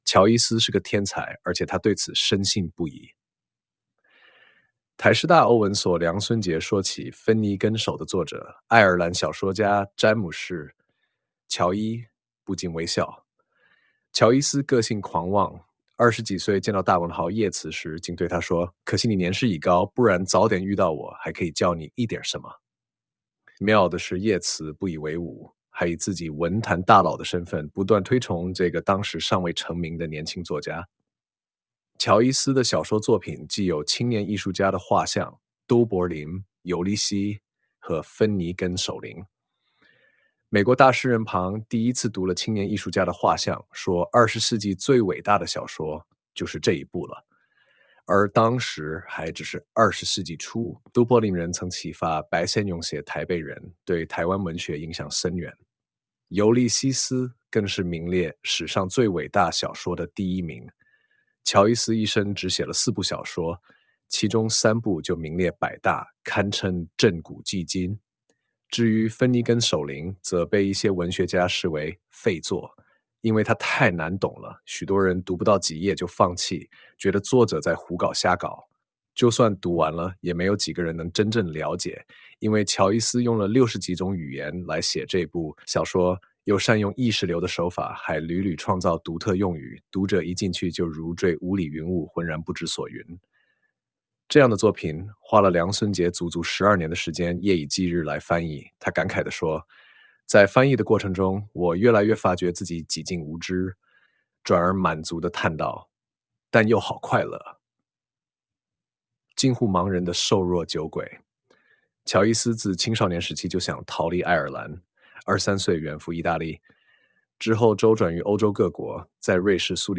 全文朗讀：